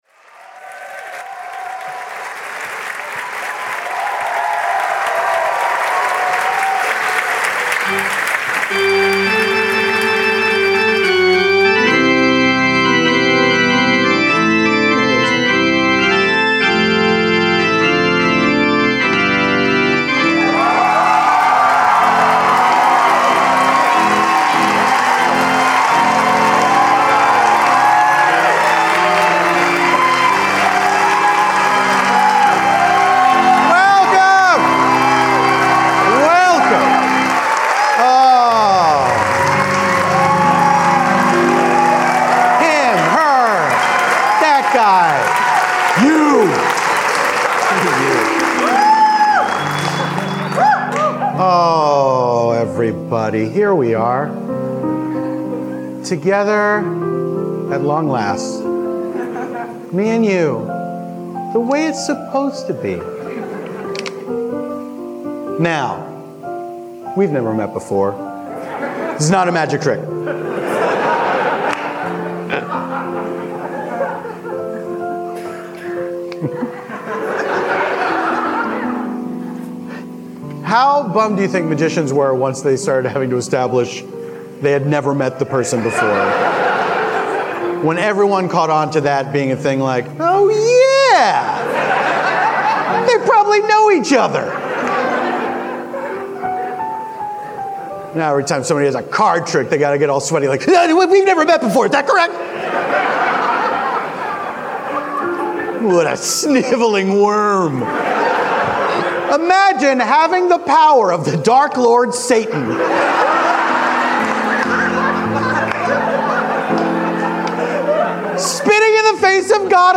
Then, they are joined by improvisers Eugene Cordero, Tawny Newsome, and Little Janet Varney, to improvise a story set at San Diego Comic-Con.
piano